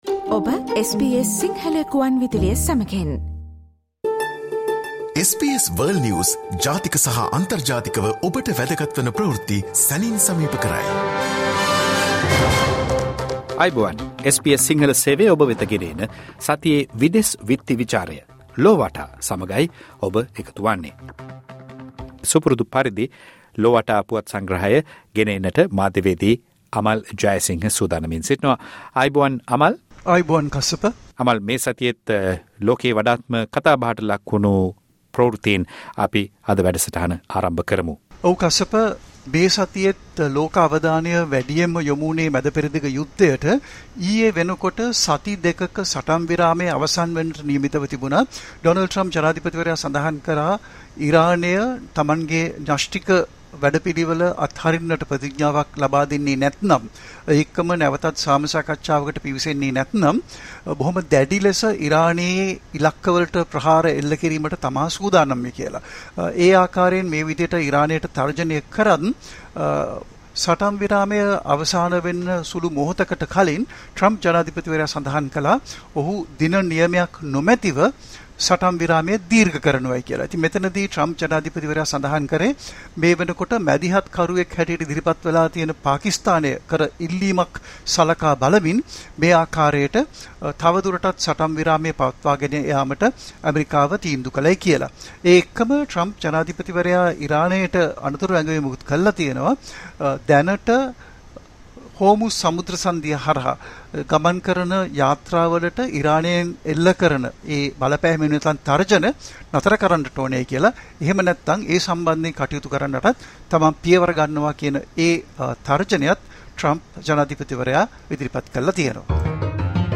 සතියේ විදෙස් පුවත් විග්‍රහය